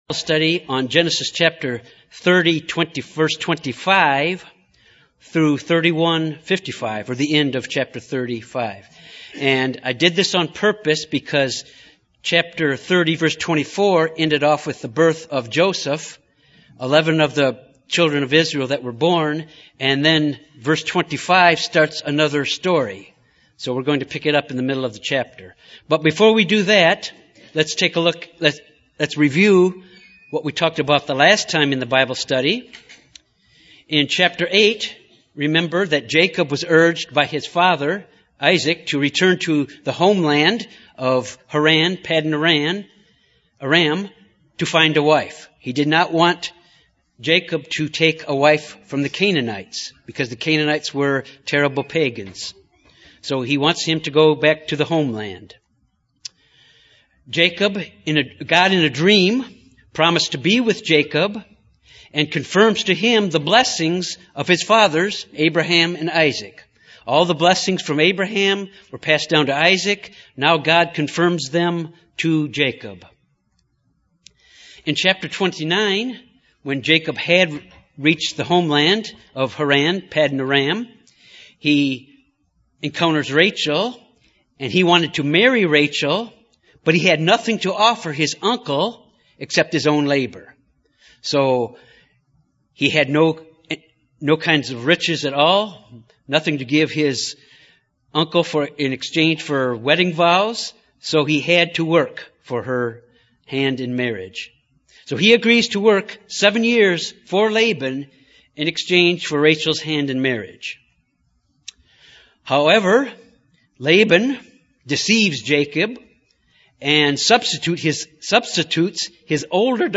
Given in Little Rock, AR
UCG Sermon Studying the bible?